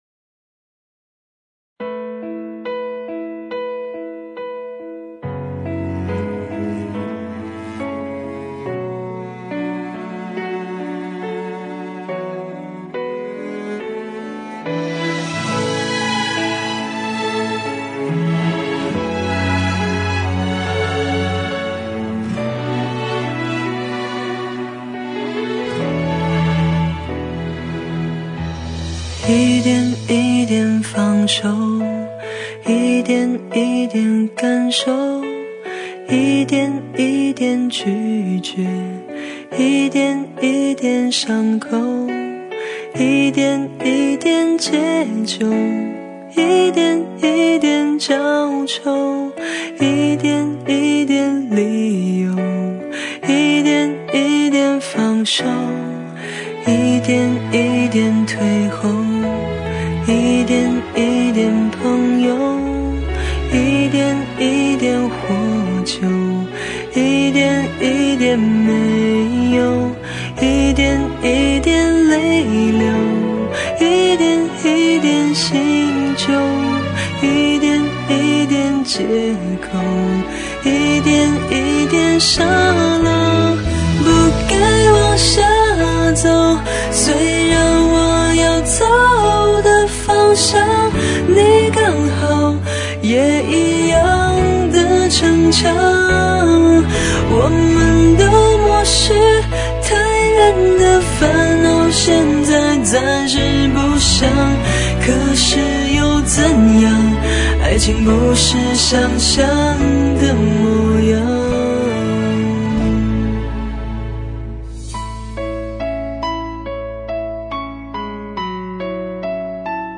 午夜，独自一人，蜷于墙角，歌声中带着的淡淡无奈、淡淡放手、淡淡心伤，缓缓，如蛆附骨，悄悄啮食着心中最柔软的一角。